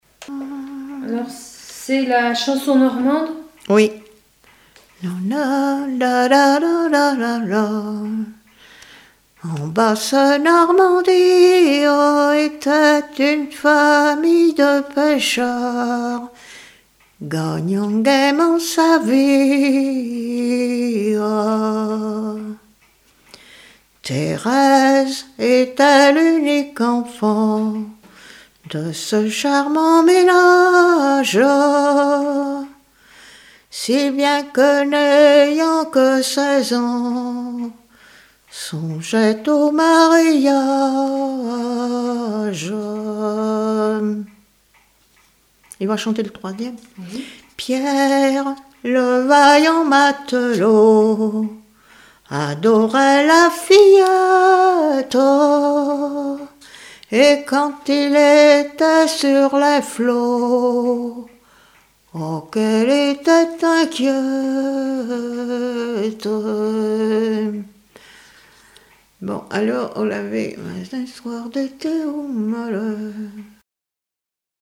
Mémoires et Patrimoines vivants - RaddO est une base de données d'archives iconographiques et sonores.
Genre strophique
Témoignages, chansons de variété et traditionnelles
Pièce musicale inédite